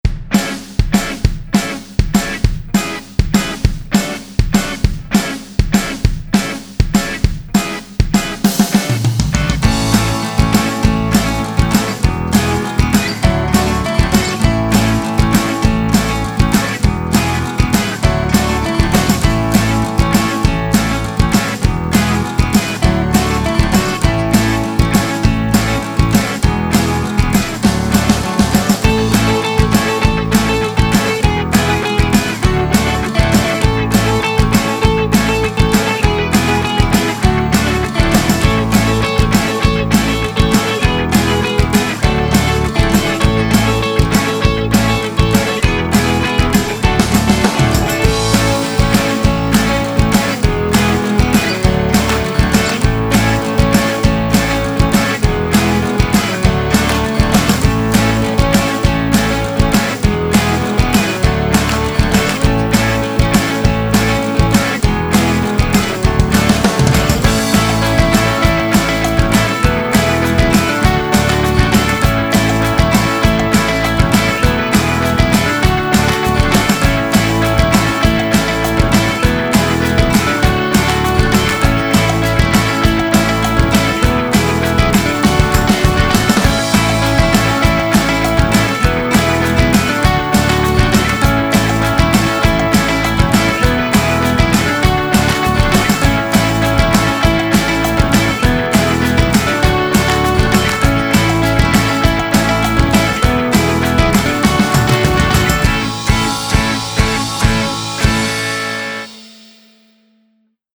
upbeat-acoustic-country-rock.mp3